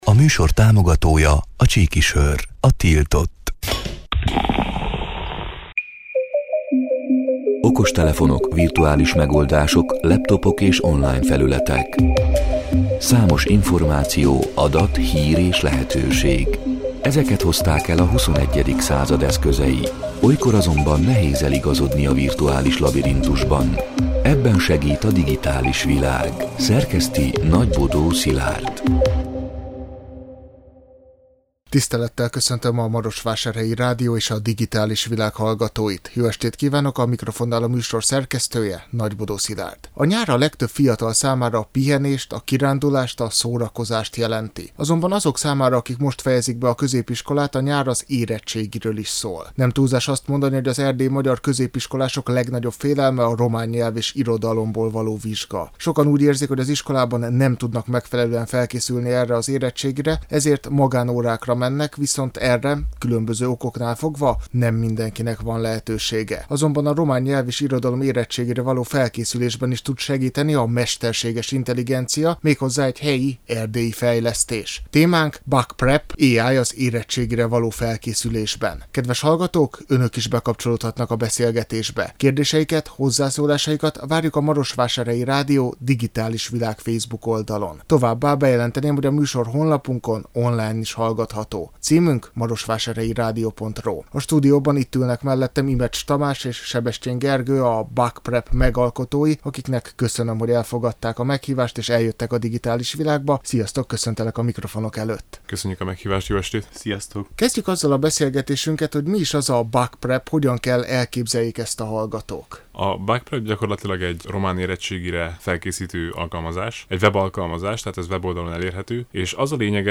A Marosvásárhelyi Rádió Digitális Világ (elhangzott: 2025. április 29-én, kedden este nyolc órától) c. műsorának hanganyaga: